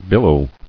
[bil·low]